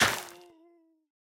Minecraft Version Minecraft Version snapshot Latest Release | Latest Snapshot snapshot / assets / minecraft / sounds / block / soul_soil / break3.ogg Compare With Compare With Latest Release | Latest Snapshot